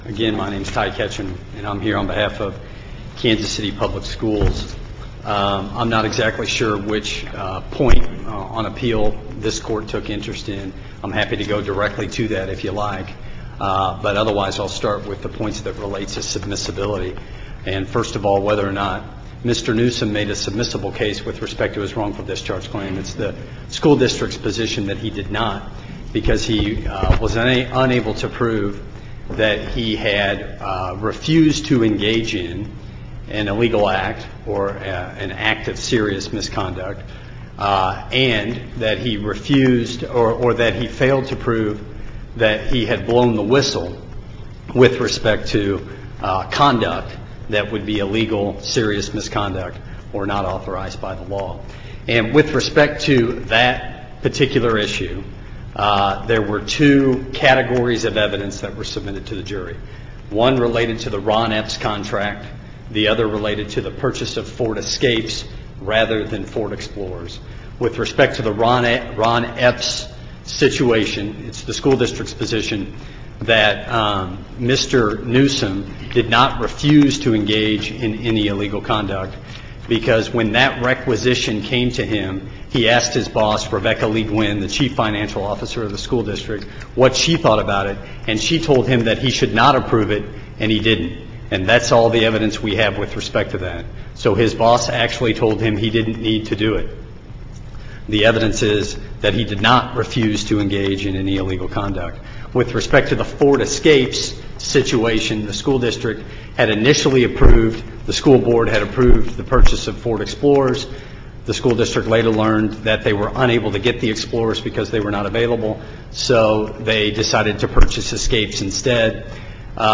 MP3 audio file of oral arguments in SC95538